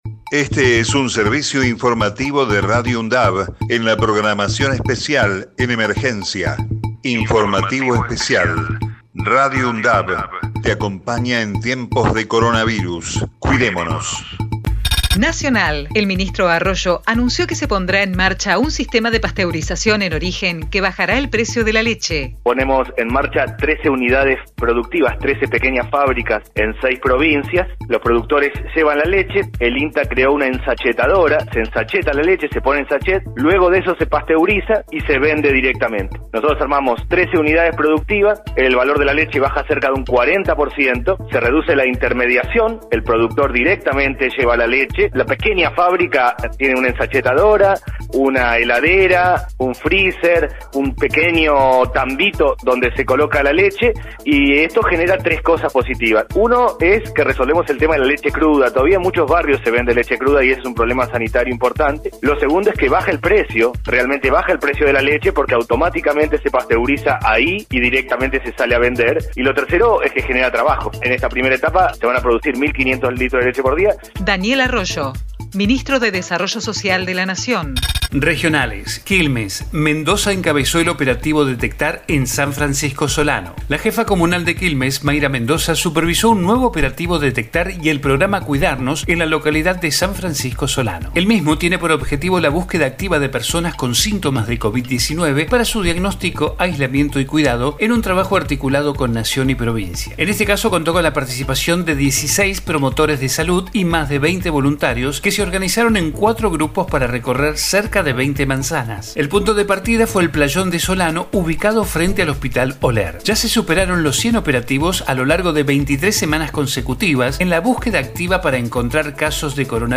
COVID-19 Informativo en emergencia 27 de octubre 2020 Texto de la nota: Este es un servicio informativo de Radio UNDAV en la programación especial en emergencia.